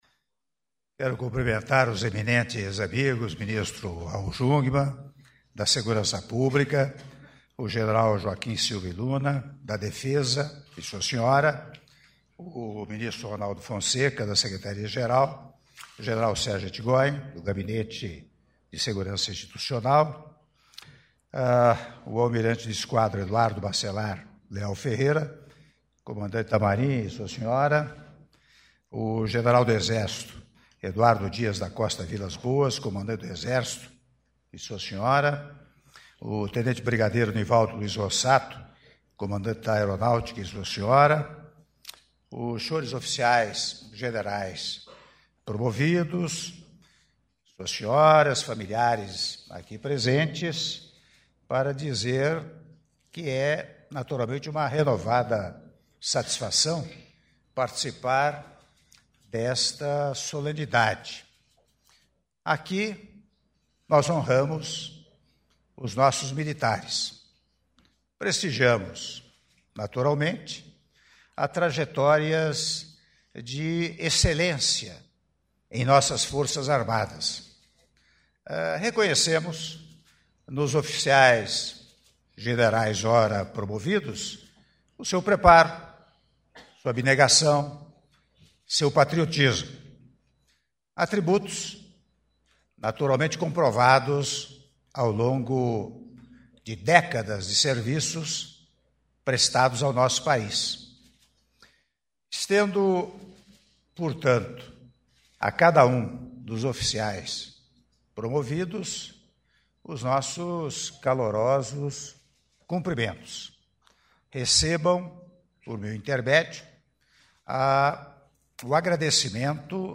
Áudio do discurso do Presidente da República, Michel Temer, durante Cerimônia de Cumprimentos aos Oficiais-Generais Recém-Promovidos - Palácio do Planalto (07min33s)